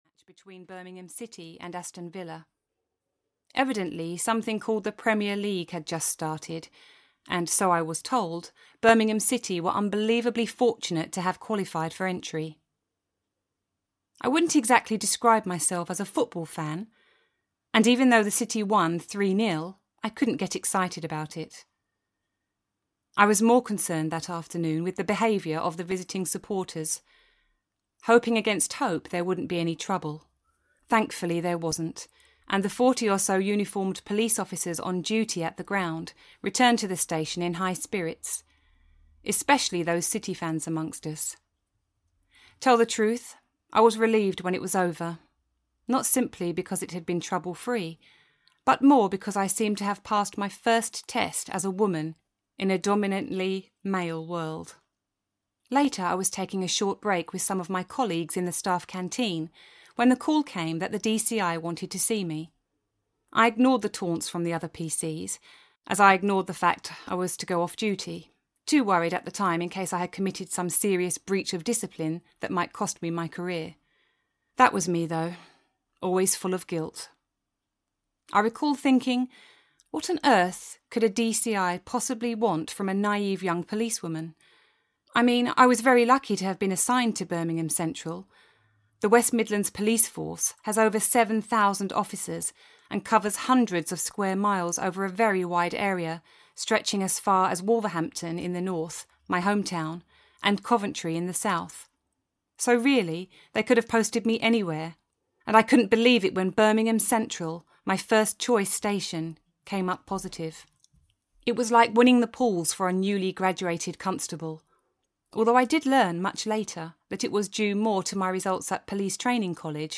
Nemesis (EN) audiokniha
Ukázka z knihy